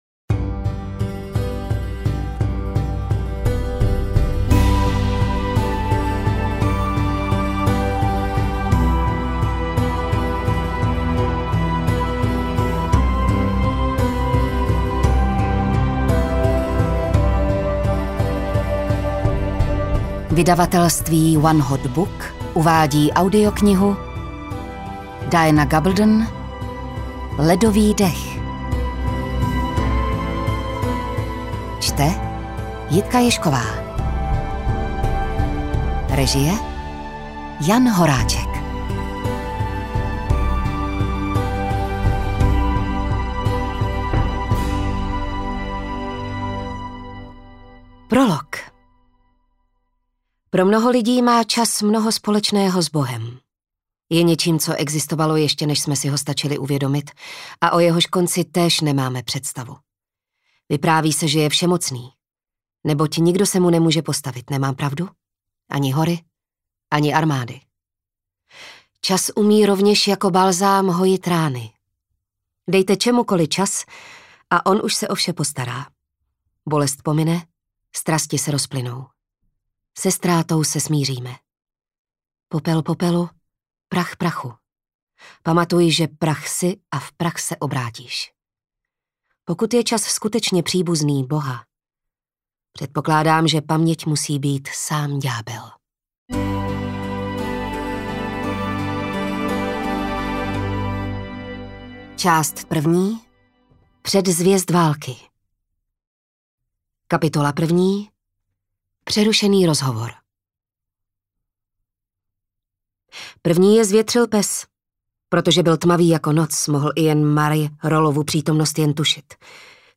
Interpret:  Jitka Ježková
AudioKniha ke stažení, 179 x mp3, délka 56 hod. 29 min., velikost 3052,0 MB, česky